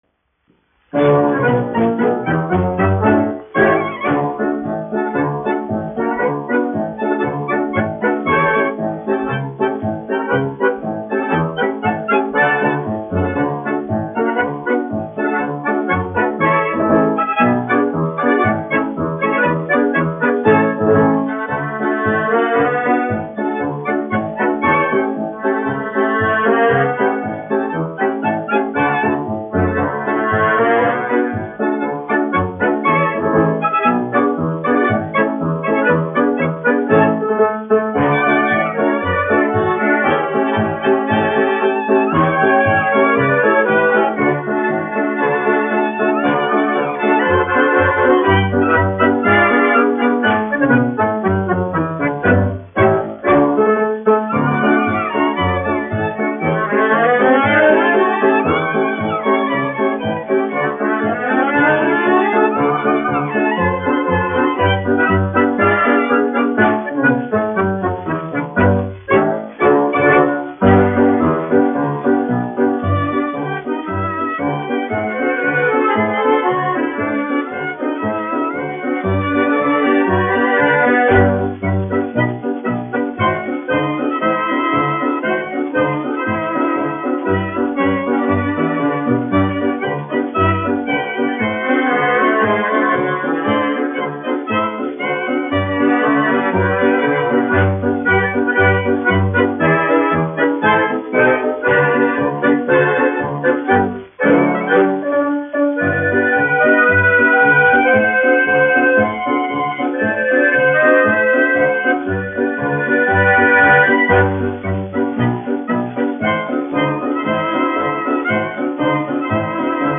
1 skpl. : analogs, 78 apgr/min, mono ; 25 cm
Marši
Skaņuplate
Latvijas vēsturiskie šellaka skaņuplašu ieraksti (Kolekcija)